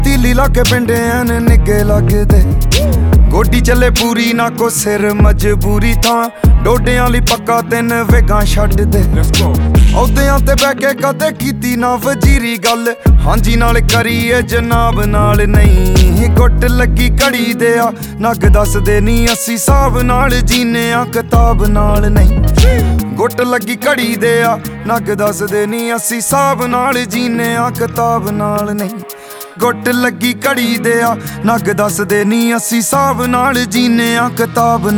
Жанр: Поп
# Punjabi Pop